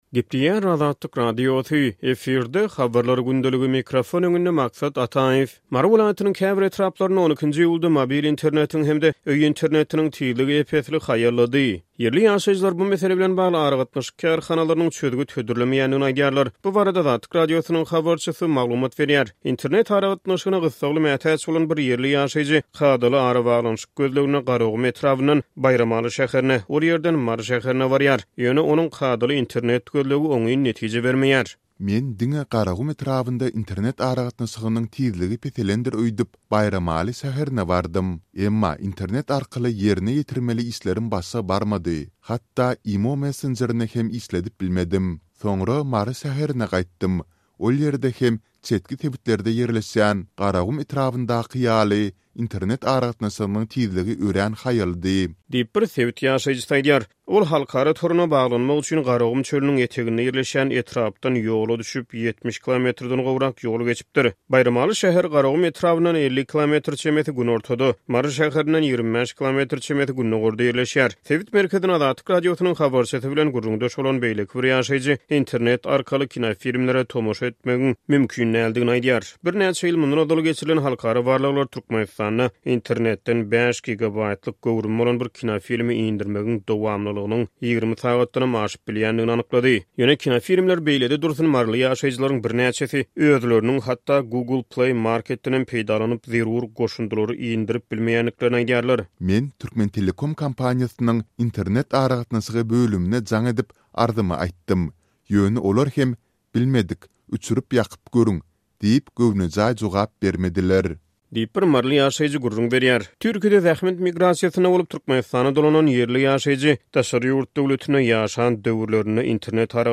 Bu barada Azatlyk Radiosynyň habarçysy maglumat berýär.